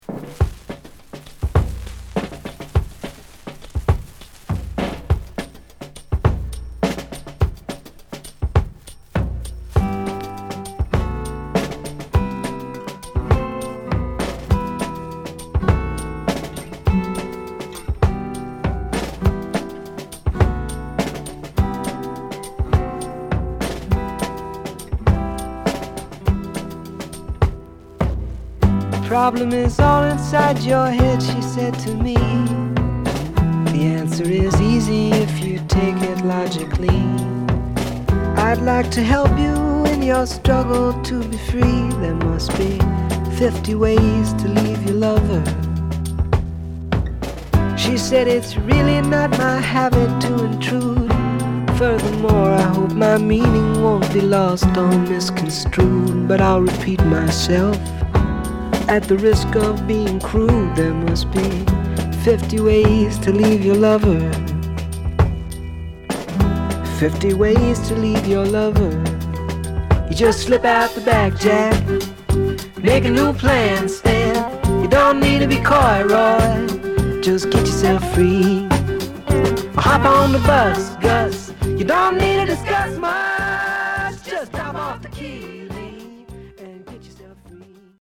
＊頭にノイズ入ります。